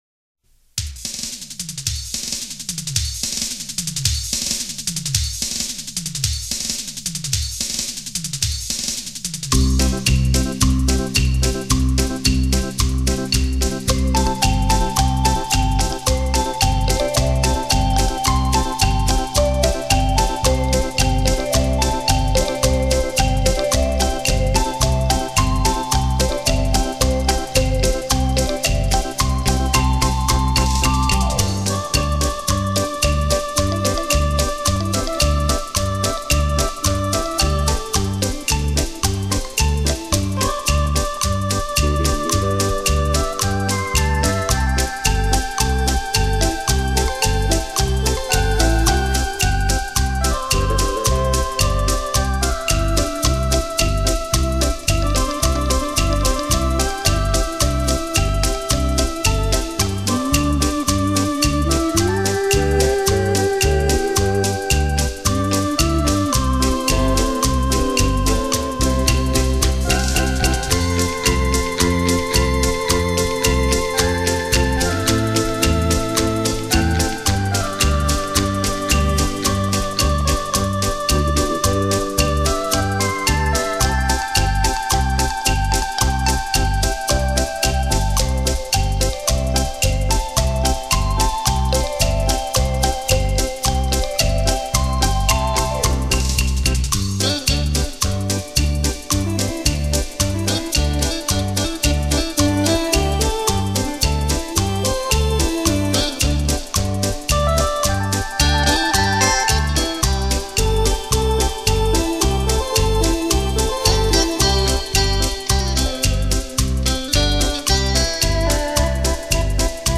本张专辑系列采用的是，结合了最新音频分离与合成技术和软件音源模拟
冲击力超强。